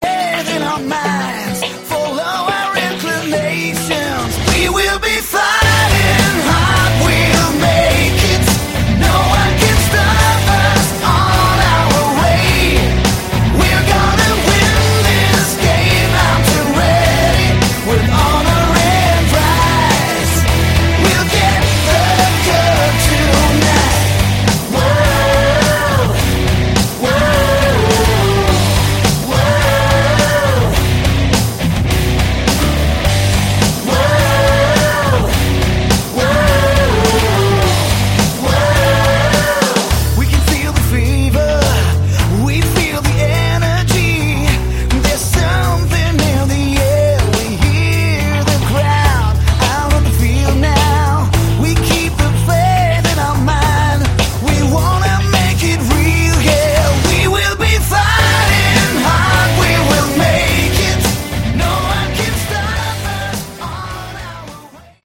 Category: Melodic Hard Rock
guitars, bass, programming
keyboards